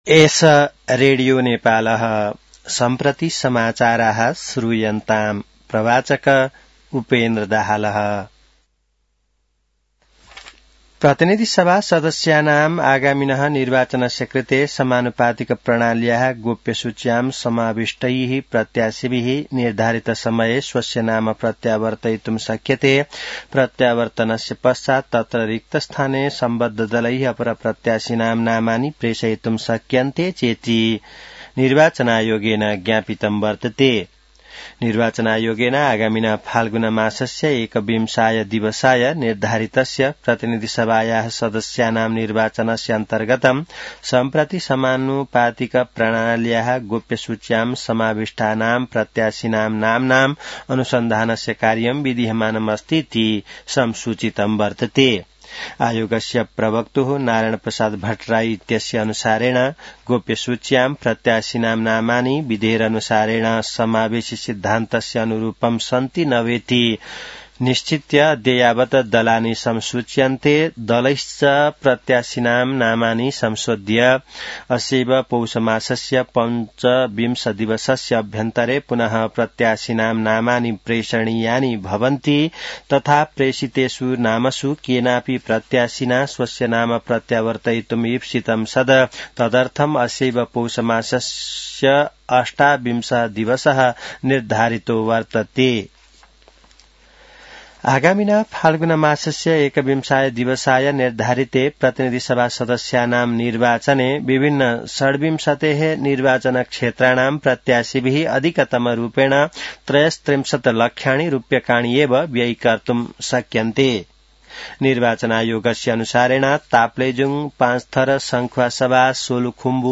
संस्कृत समाचार : २० पुष , २०८२